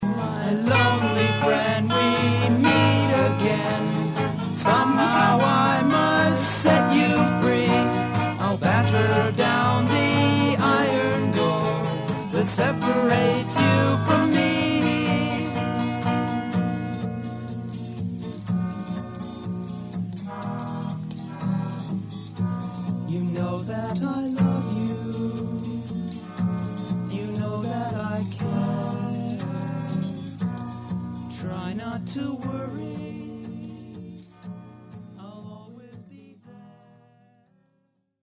original demo